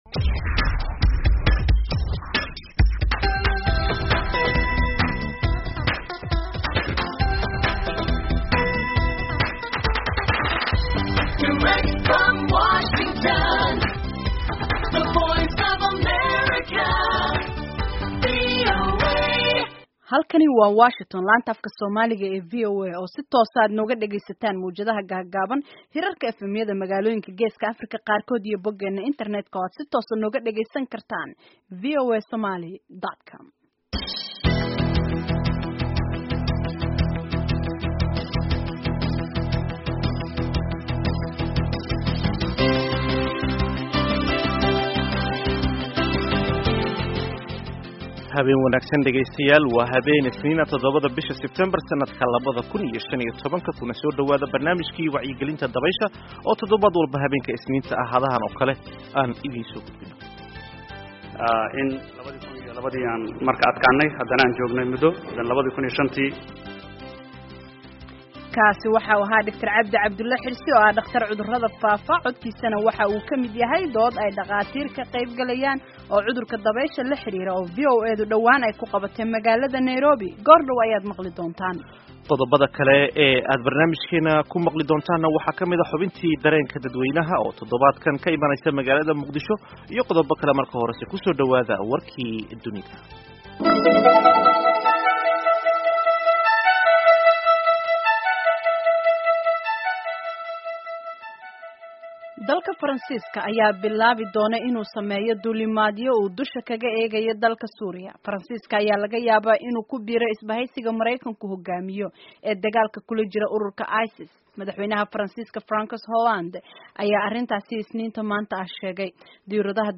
Barnaamijka Wacyigelinta ee Dabaysha waxaa uu ku saabsanyahay Kulan wacyigalin ah ooo looga hadlayo cudurka Dabaysha oo ay VOA ku qabatay Nairobi.